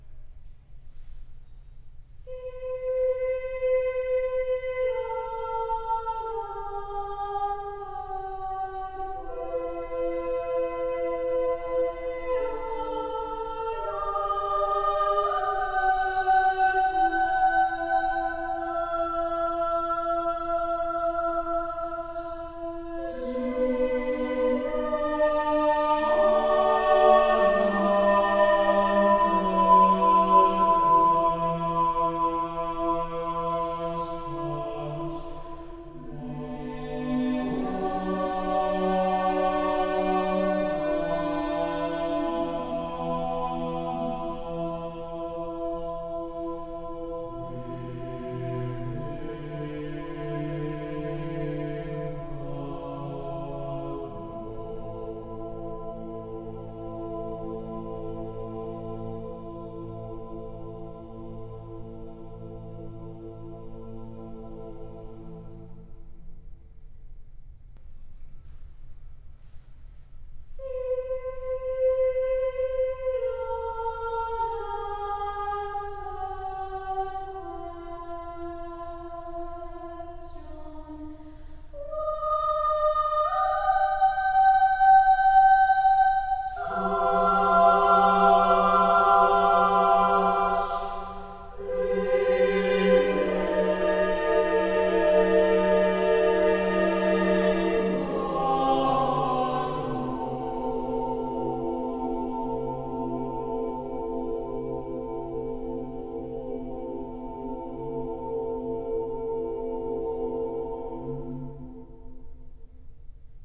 6-channel sound installation Approximately 3 minutes, looped